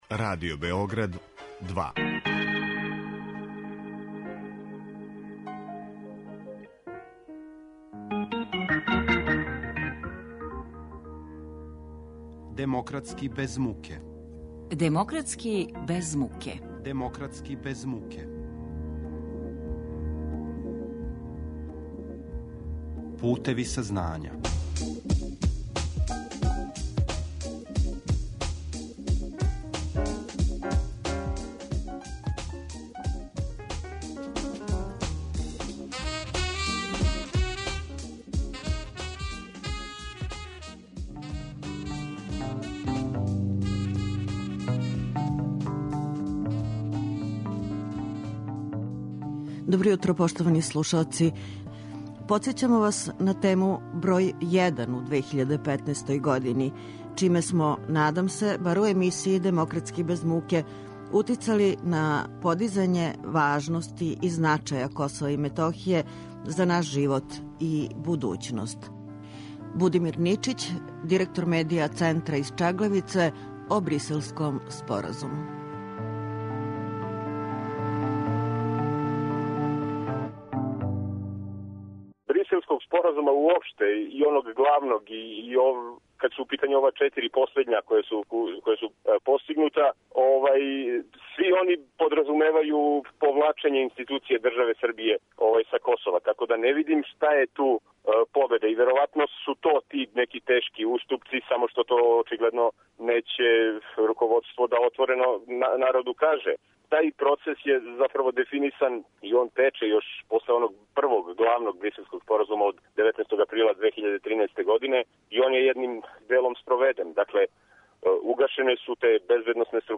У данашњем издању емисије Демократски без муке, слушаћете звучне записе са Косова и Метохије, преглед коментара, утисака и животних слика, забележених током 2015. године.